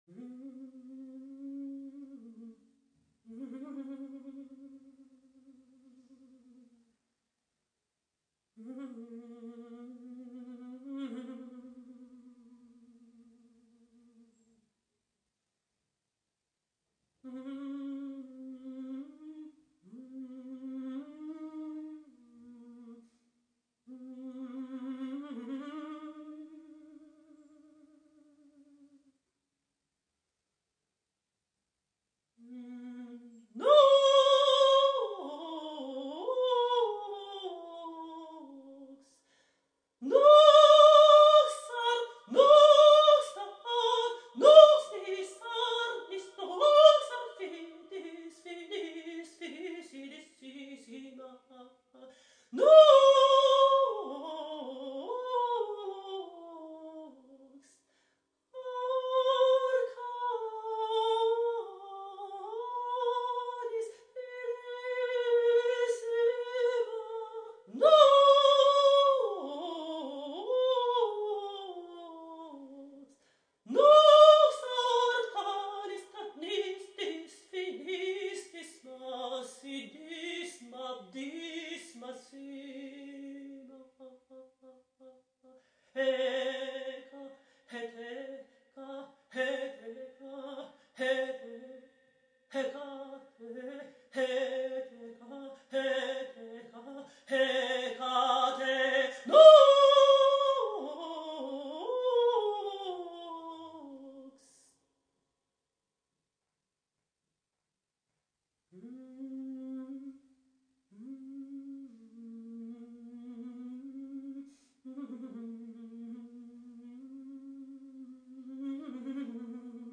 for female voice